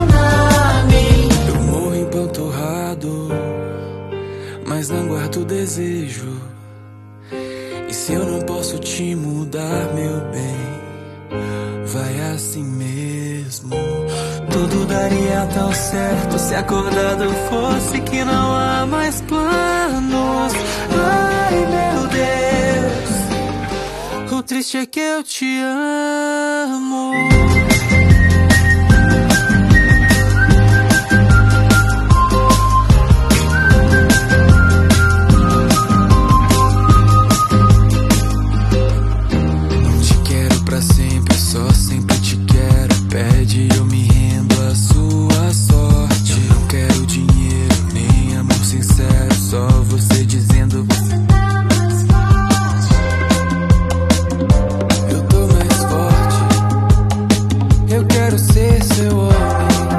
Versão Rock